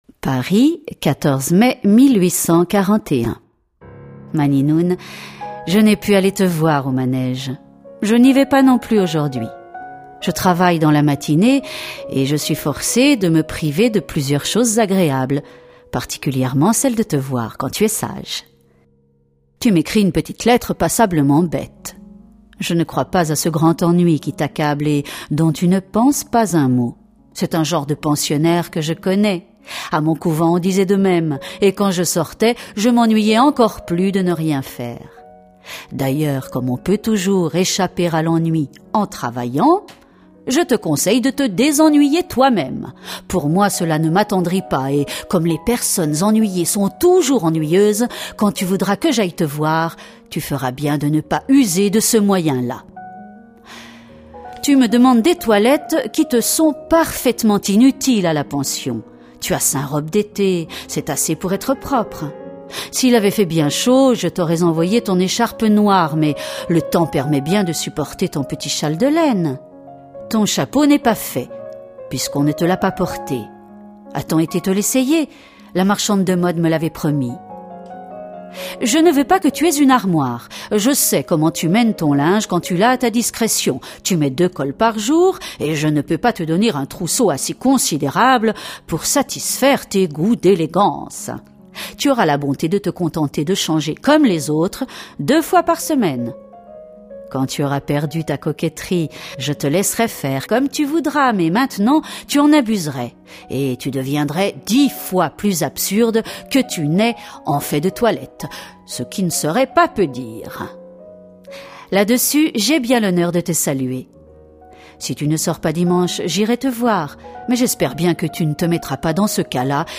Voix off
Lectrice
Narratrice